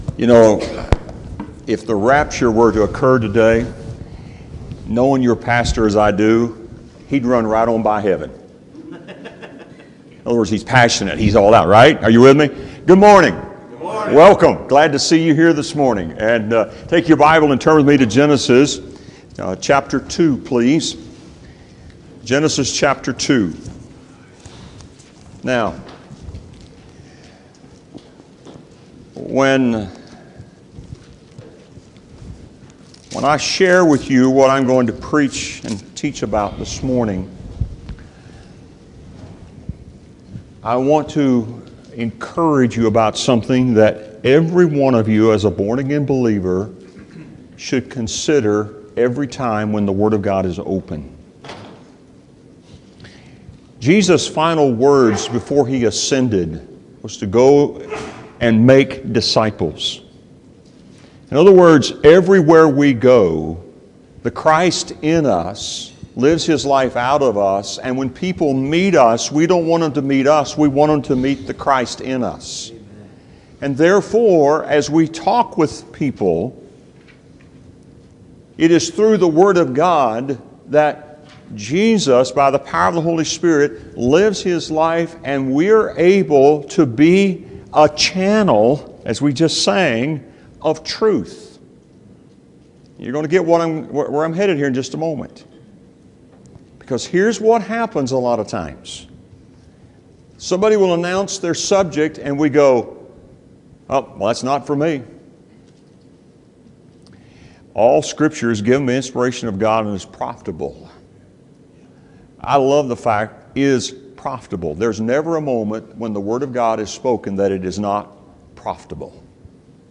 Passage: Genesis 2:18-25 Service Type: Sunday School Hour Topics